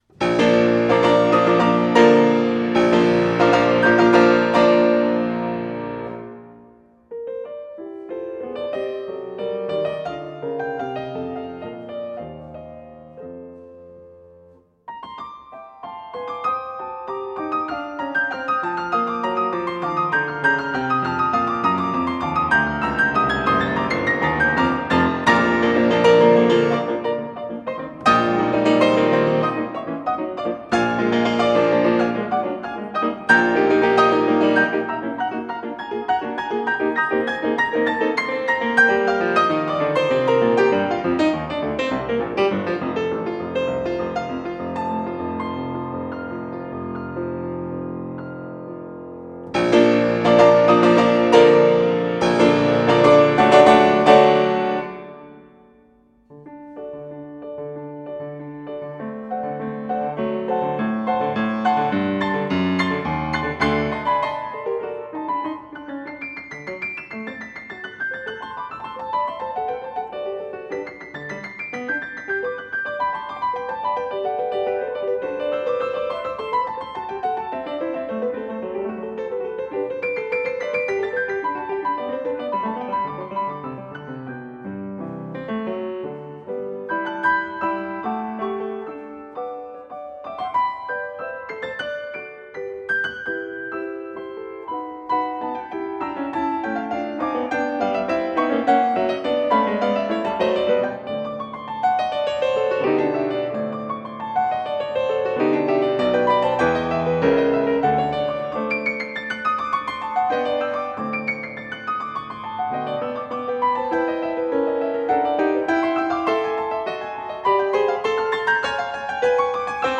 Beethoven, Ludwig van - Piano Sonata No.29 in Bb major, Op.106 (Hammerklavier) Free Sheet music for Piano